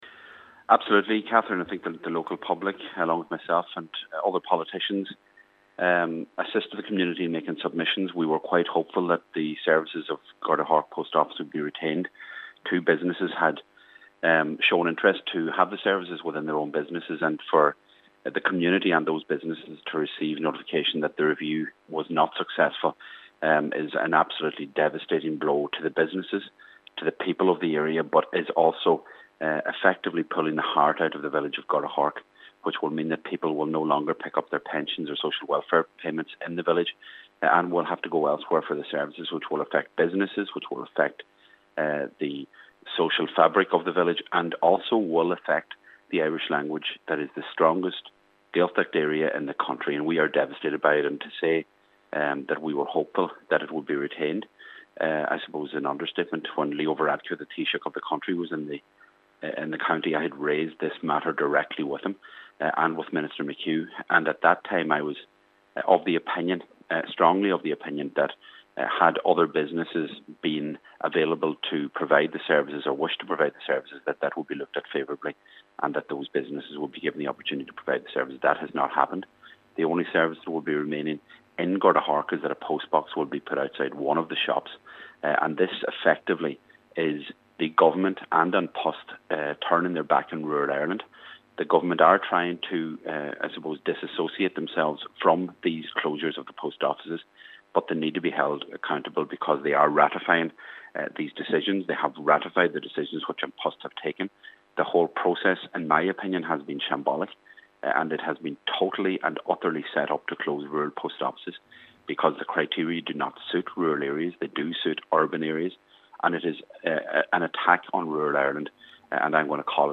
Cathaoirleach of Donegal County Council Cllr Seamus O’Domhnaill says the community are devastated: